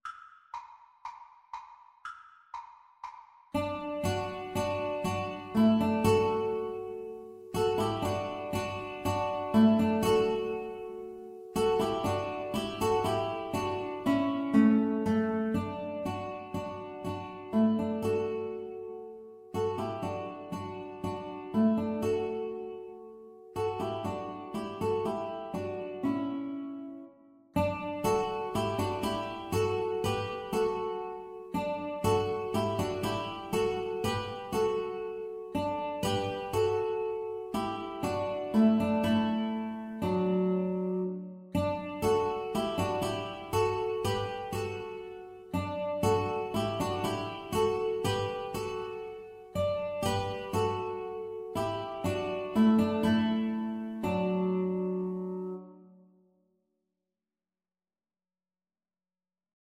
D major (Sounding Pitch) (View more D major Music for Guitar Trio )
Allegro (View more music marked Allegro)
Classical (View more Classical Guitar Trio Music)